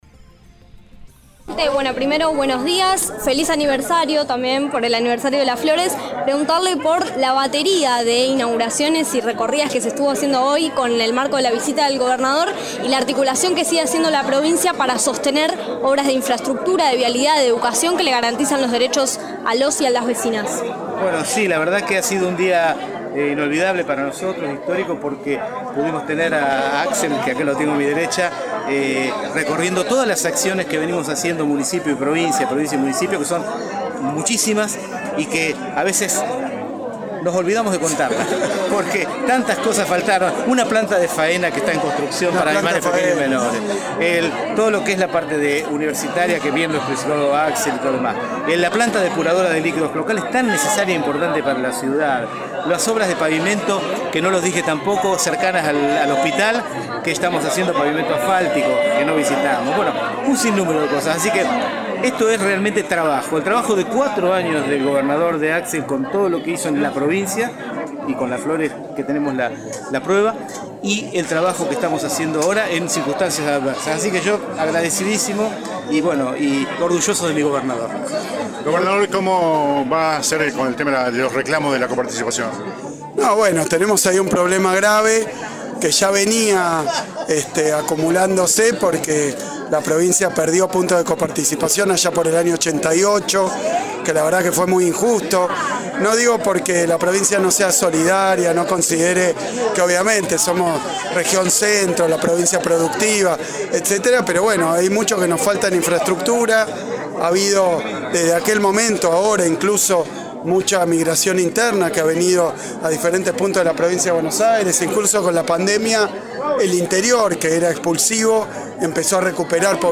Conferencia-Kiciloff.mp3